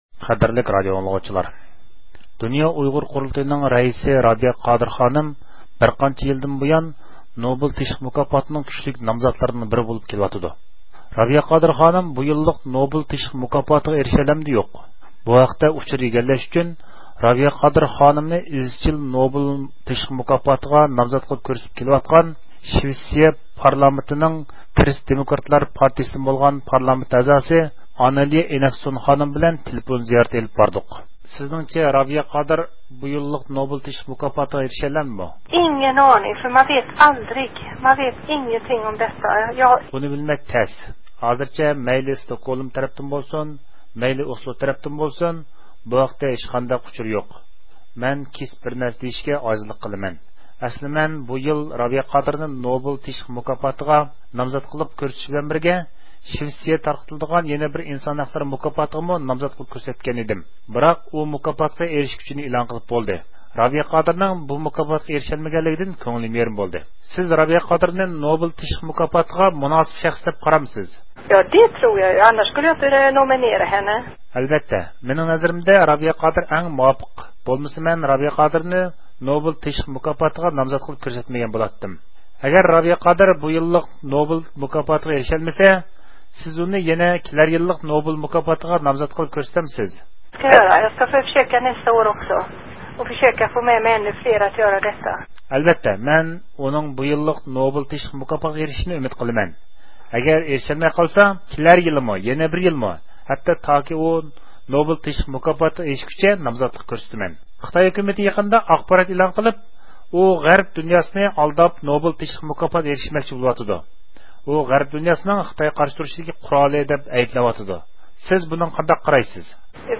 رابىيە قادىر خانىم بۇ يىللىق نوبېل تىنچلىق مۇكاپاتىغا ئىرىشەلەمدۇ – يوق، بۇ ھەقتە ئۇچۇر ئىگىلەش ئۈچۈن رابىيە قادىر خانىمنى ئىزچىل نوبېل تىنچلىق مۇكاپاتىغا نامزات قىلىپ كۆرسىتىپ كېلىۋاتقان شىۋېتسىيە پارلامېنتىنىڭ كرستدېموكراتلار پارتىيىسىدىن بولغان پارلامېنت ئەزاسى ئانالېيە ئېناكسون خانىم بىلەن تېلېفون زىيارىتى ئېلىپ باردۇق.